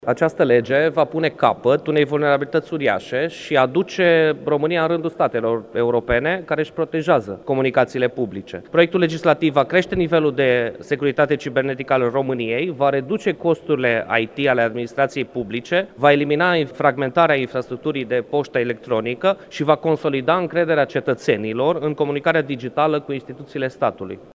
Vasile-Ciprian Rus, președintele Comisiei pentru comunicații: „Va pune capăt unei vulnerabilități uriașe”